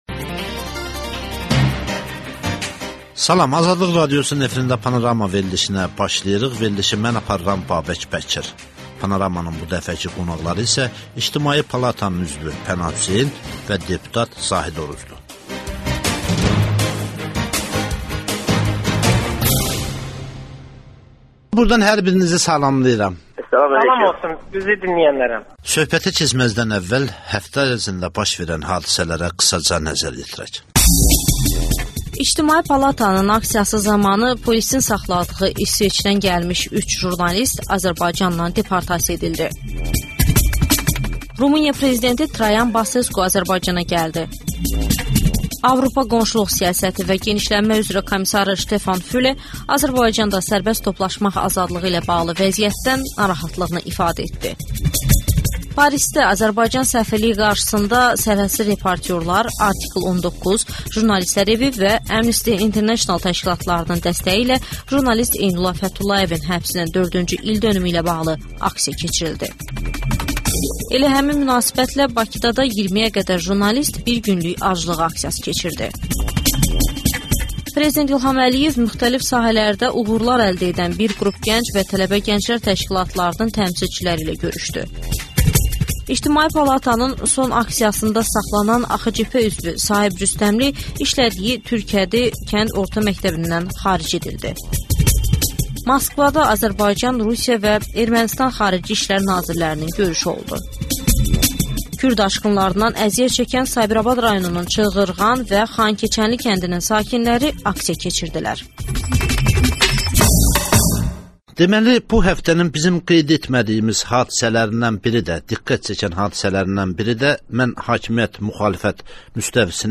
«Panorama» verilişində suallara İctimai Palatanın üzvü Pənah Hüseyn və deputat Zahid Oruc cavab verir.